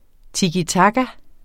Udtale [ tigiˈtɑgɑ ]